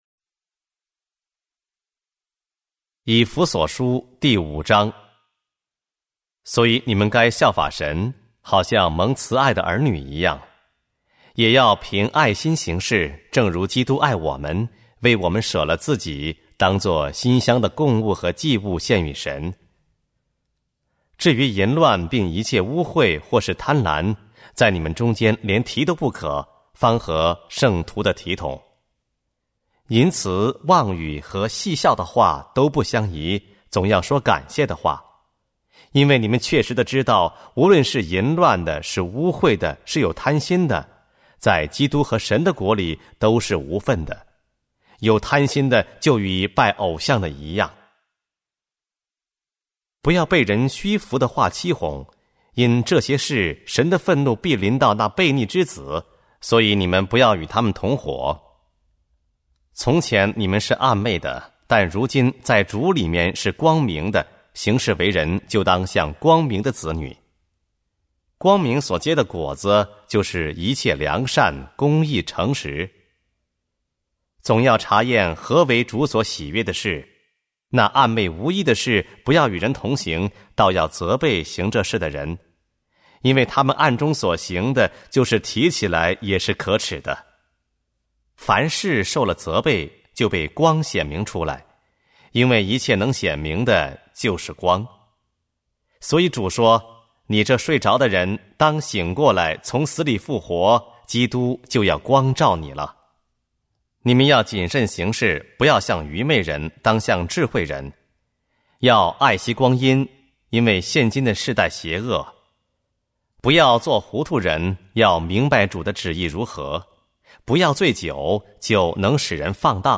和合本朗读：以弗所书